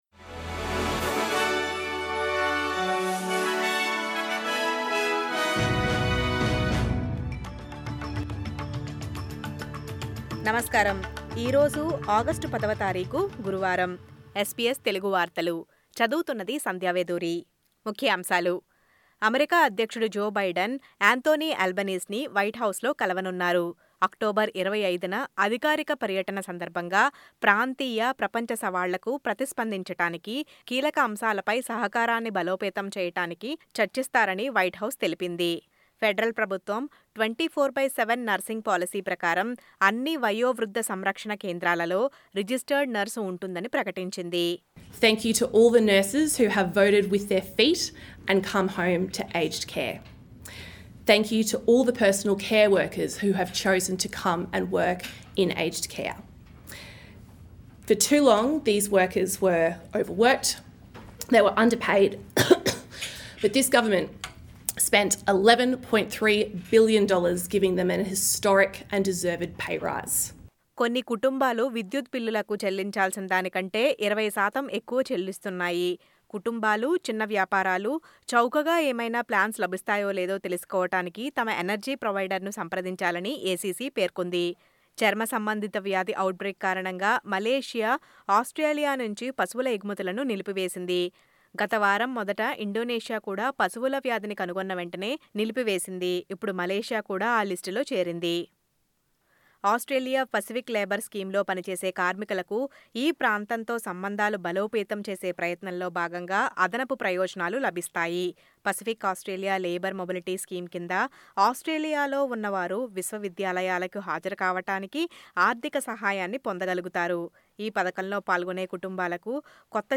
SBS Telugu వార్తలు.